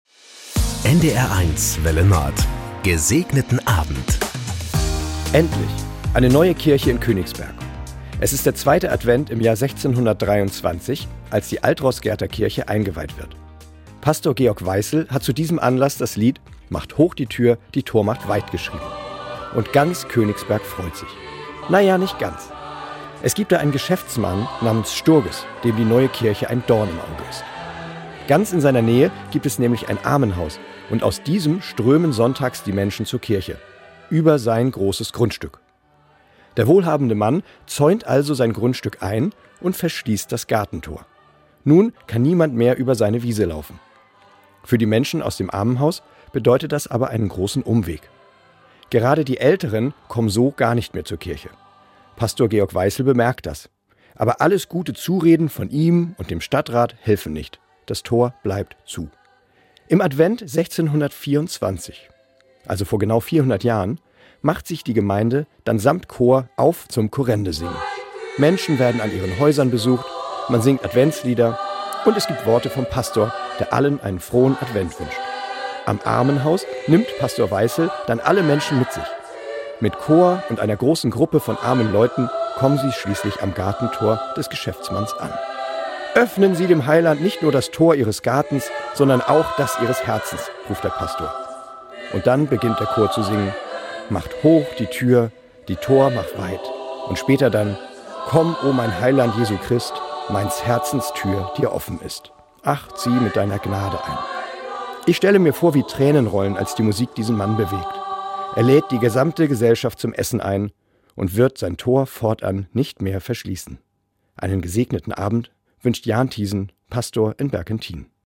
Das gute Wort zum Feierabend auf NDR 1 Welle Nord mit den Wünschen für einen "Gesegneten Abend". Von Sylt oder Tönning, Kiel oder Amrum kommt die Andacht als harmonischer Tagesabschluss. Täglich um 19.04 Uhr begleiten wir Sie mit einer Andacht in den Abend - ermutigend, persönlich, aktuell, politisch, tröstend.